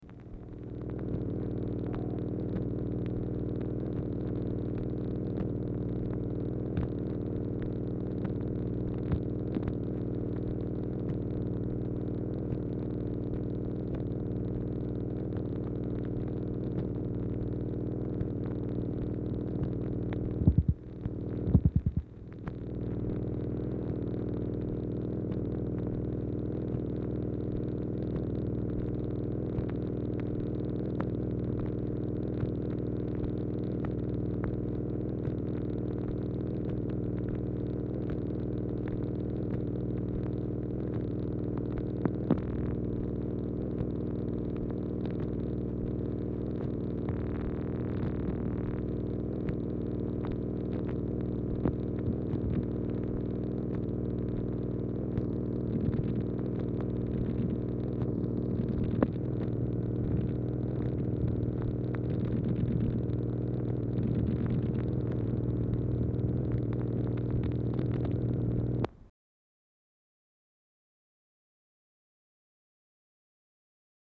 Telephone conversation
MACHINE NOISE
Format Dictation belt